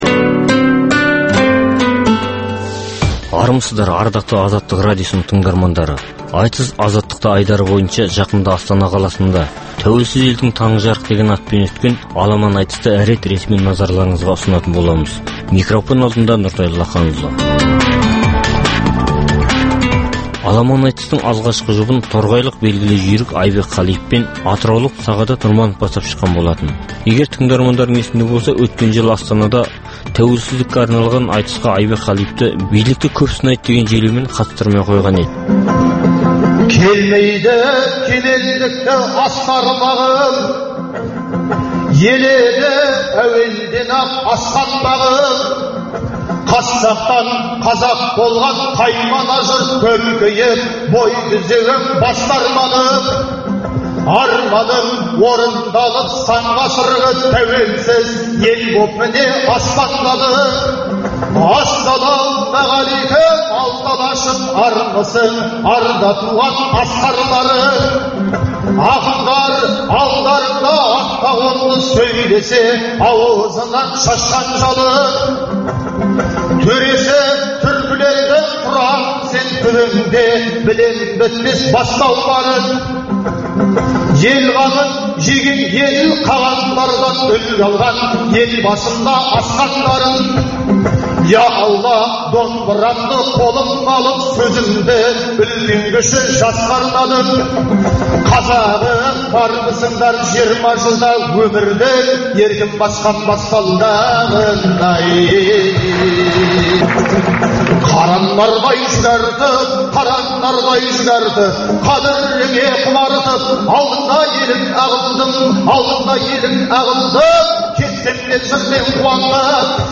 Айтыс - Азаттықта
Аламан айтысқа қазақстандық ақындармен қатар Қырғызстан, Моңғолия және Қытай елінен айтыскерлер келді.